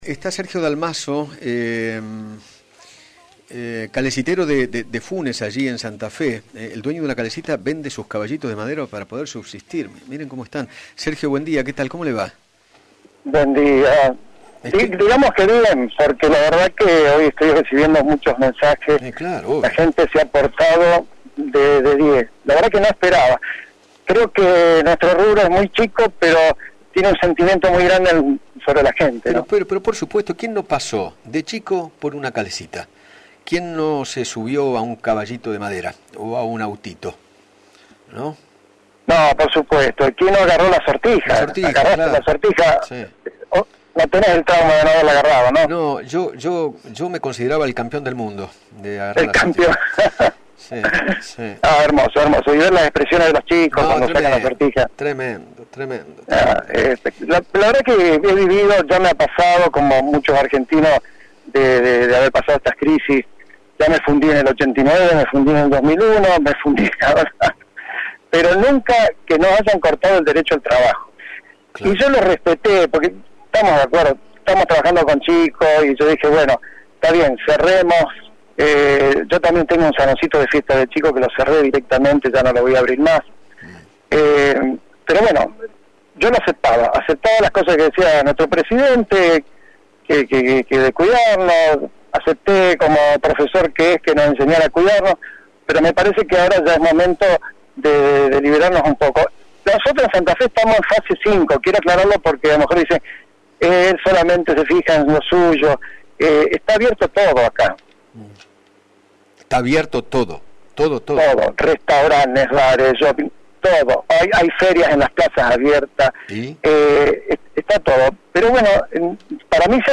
dialogó con Eduardo Feinmann sobre la venta de los caballos de su calesita para paliar la crisis económica y  expresó que “acepté en un principio lo de cuidarnos